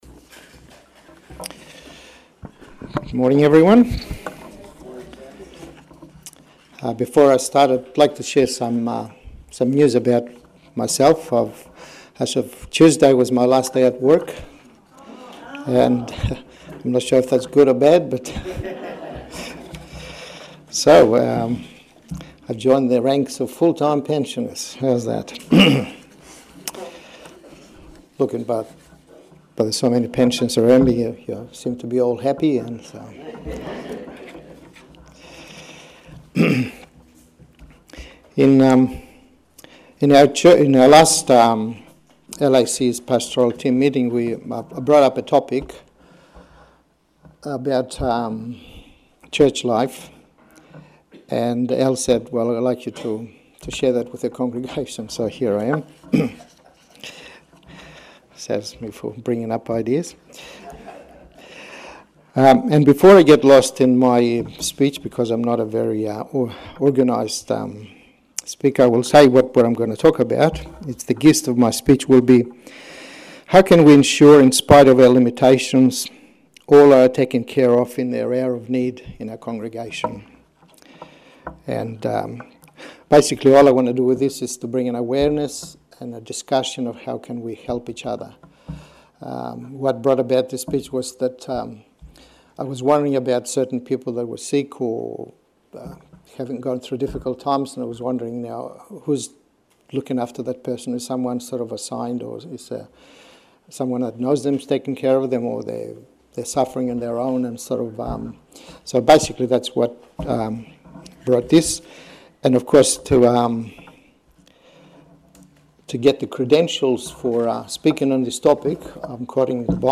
A message from the series "Messages-ChurchLife."